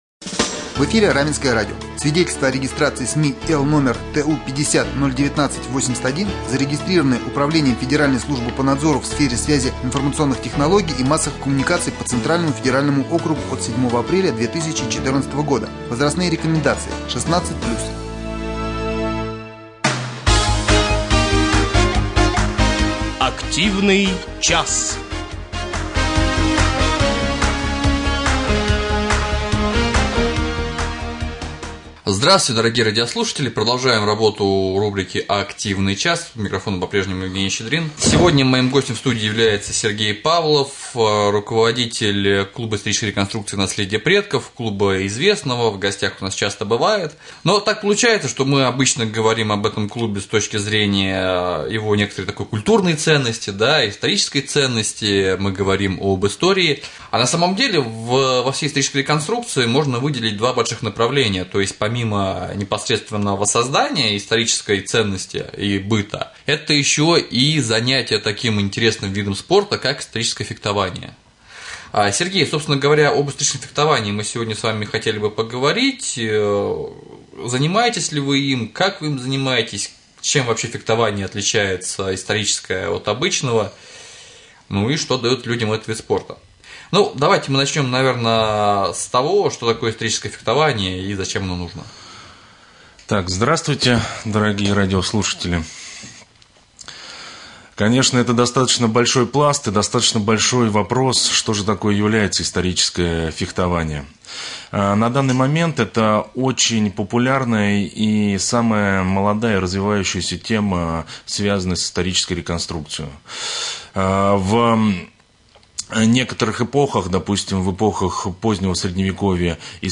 Гости студии